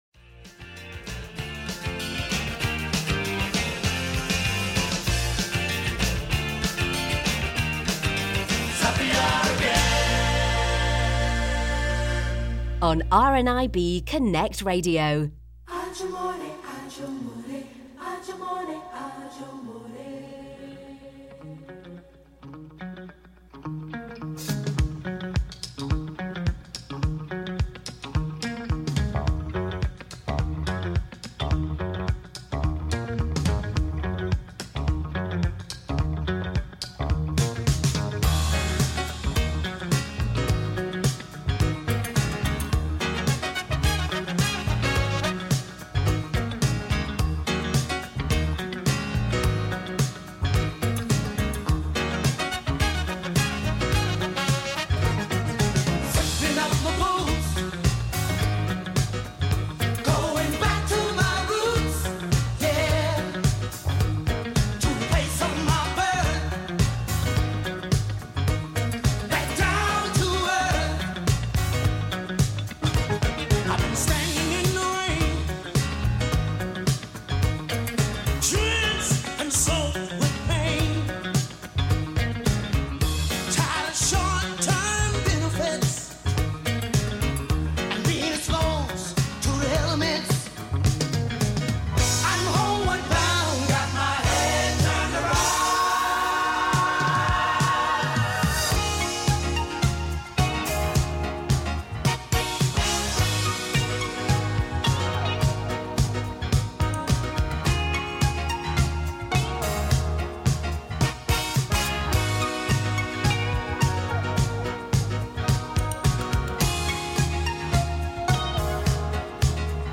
Fox Calls
Robin Singing